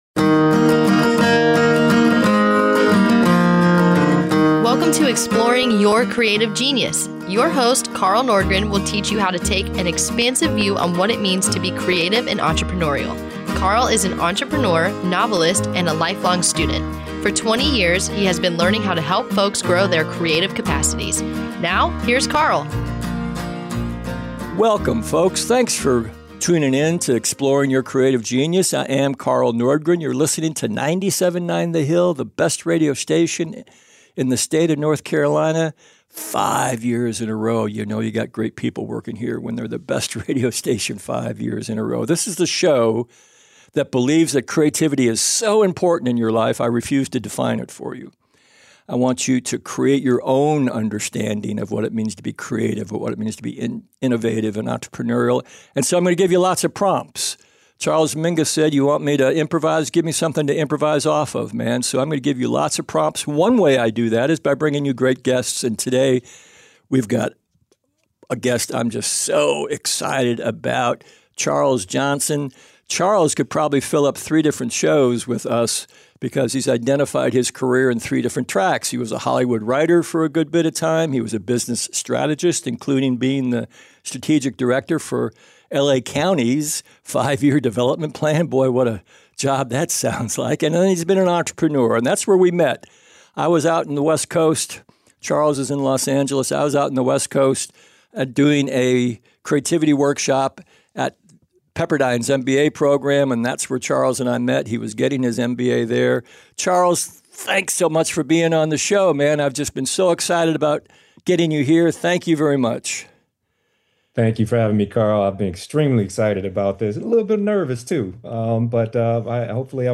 ongoing conversation